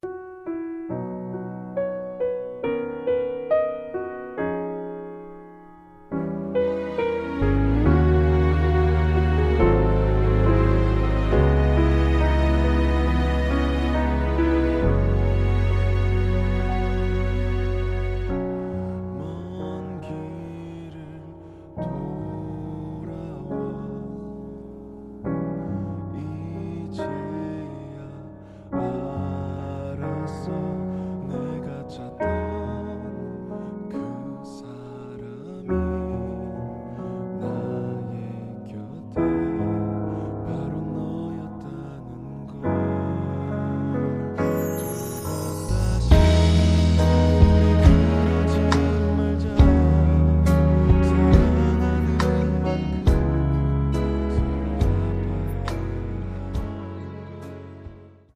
음정 -1키 4:11
장르 가요 구분 Voice Cut